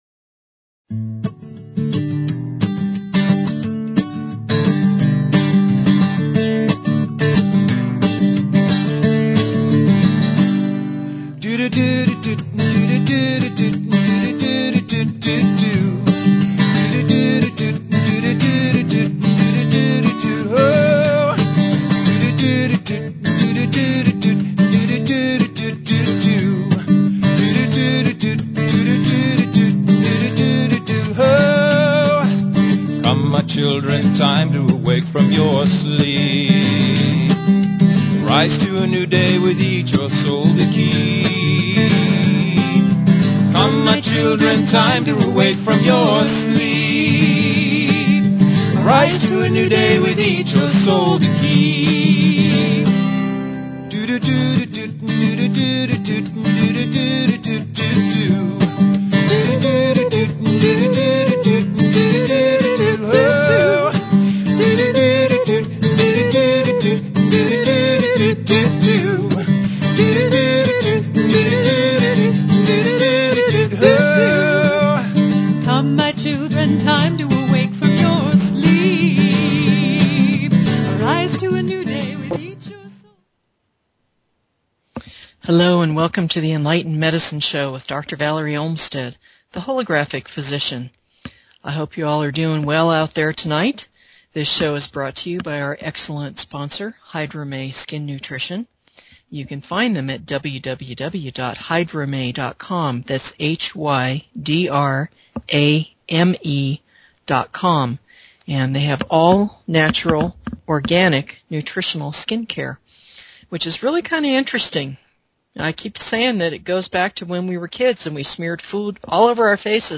Talk Show Episode, Audio Podcast, Enlightened_Medicine and Courtesy of BBS Radio on , show guests , about , categorized as
We'll start the show with a discussion on things that go wrong. The last portion of the show will be a 5th Chakra meditation; join in and speak your Truth!